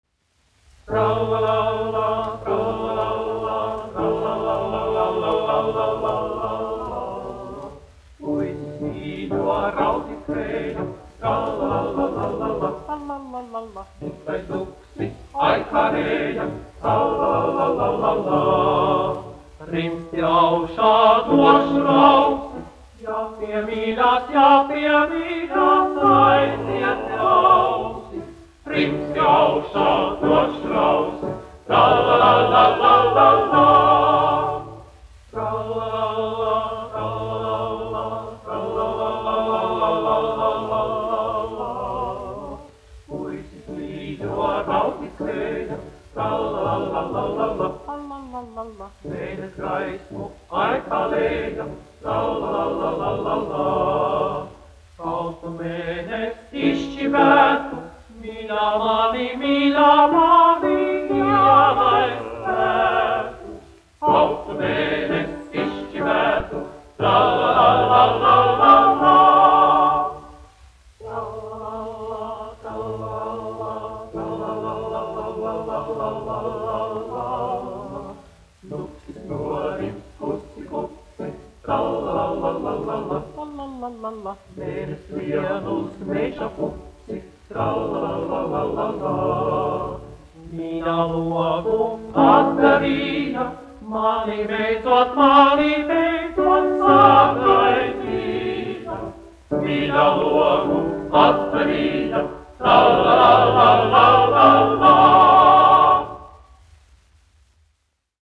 Puisis mīļo raudzīt gāja : čehu tautas dziesma
Latvijas Filharmonijas vīru vokālais dubultkvartets, izpildītājs
1 skpl. : analogs, 78 apgr/min, mono ; 25 cm
Čehu tautasdziesmas
Latvijas vēsturiskie šellaka skaņuplašu ieraksti (Kolekcija)